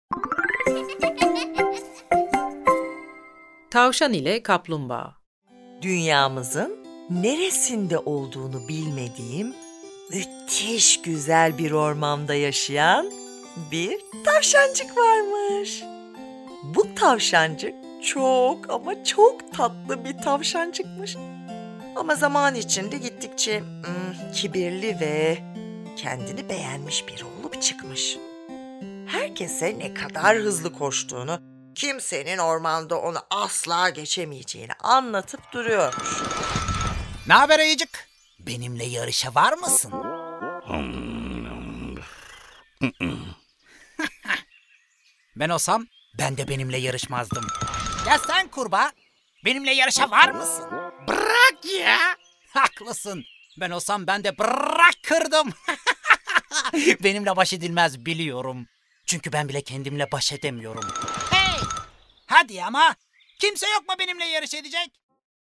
Tavşan ile Kaplumbağa Tiyatrosu